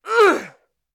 Groan Female Sound
human
Groan Female